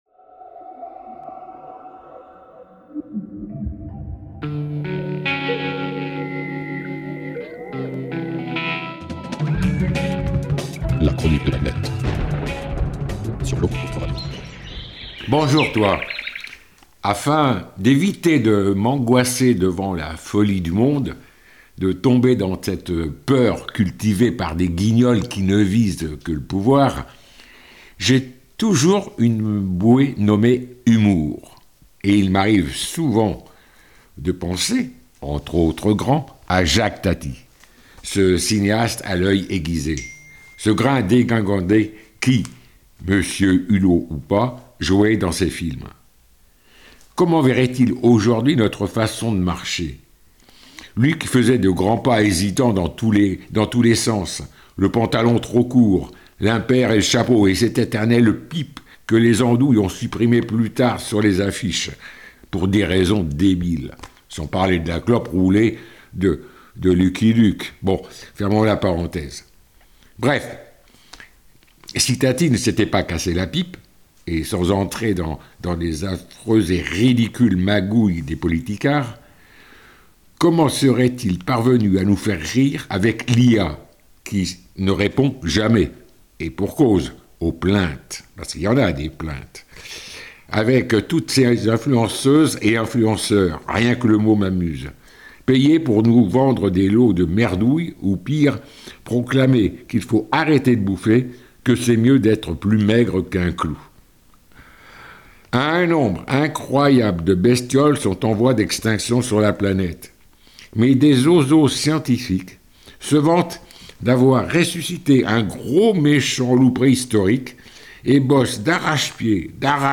Quelques petites minutes pour un billet d'humeur style " j'aime, je partage " et autres pirouettes contre la bêtise humaine quand elle colle à nos semelles.